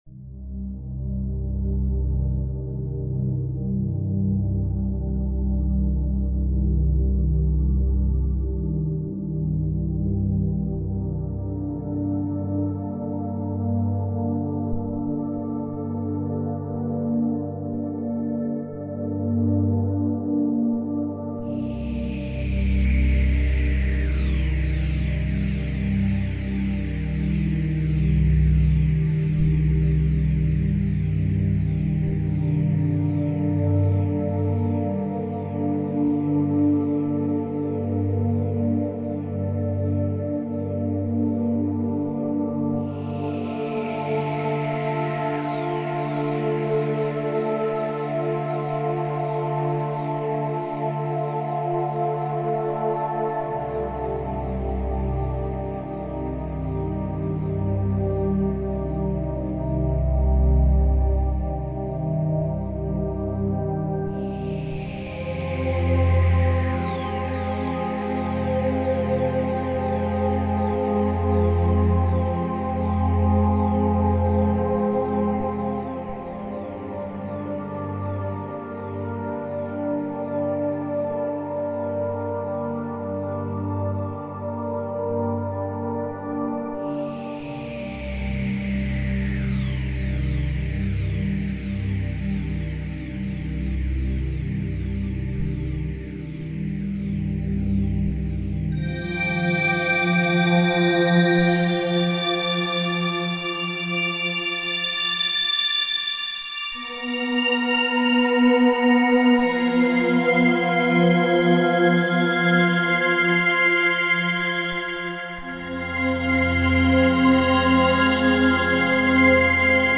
Recorded at Magnetic5280 Studios